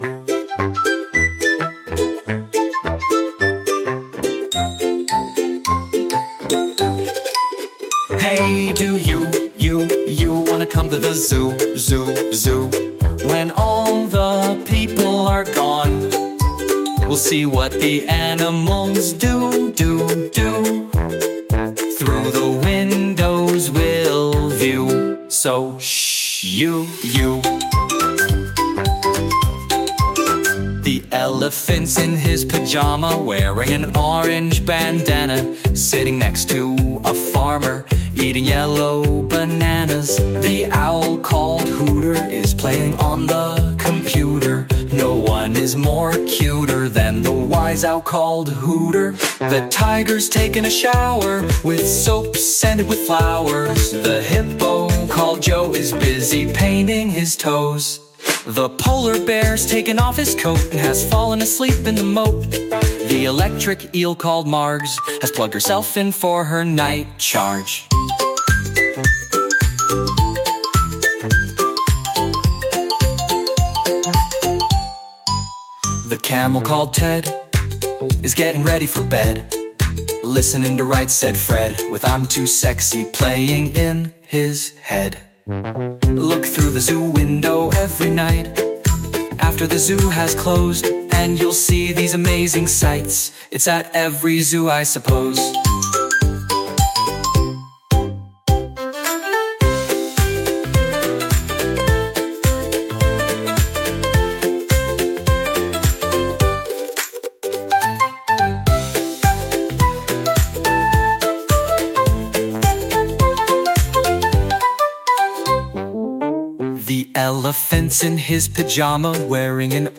whimsical and imaginative children’s song